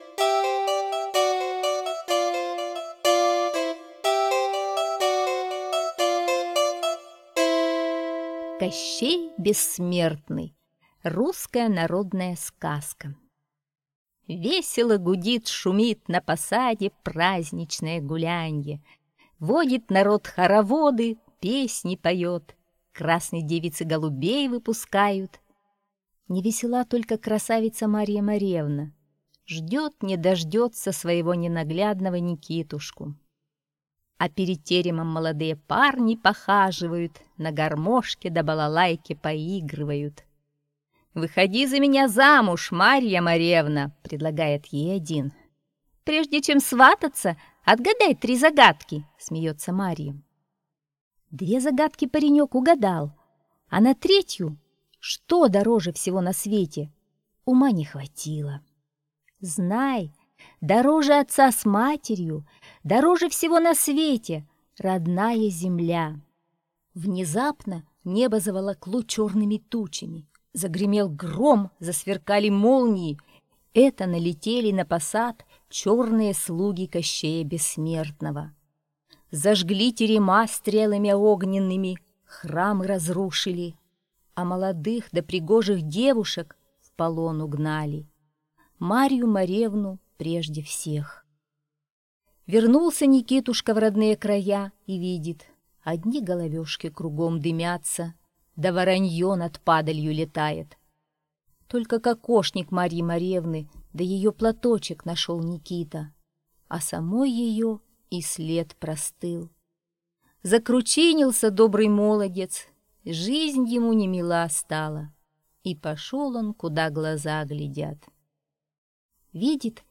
Кащей Бессмертный - русская народная аудиосказка - слушать онлайн